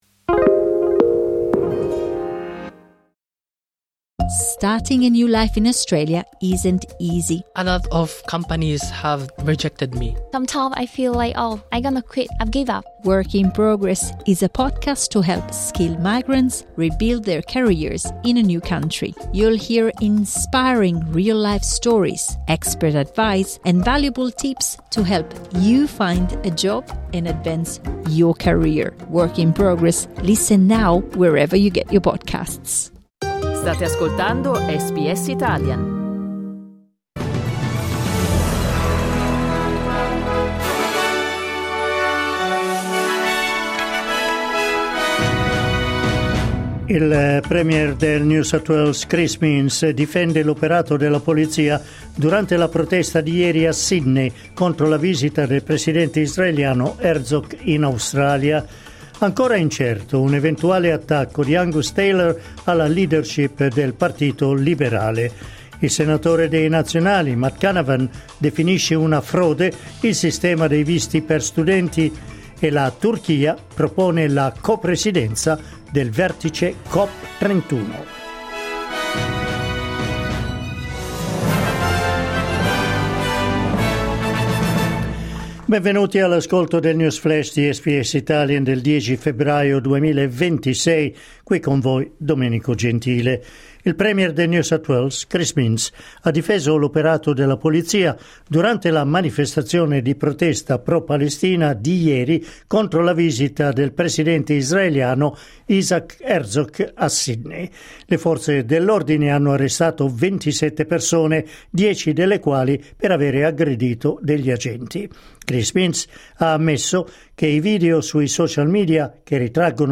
News Flash SBS Italian.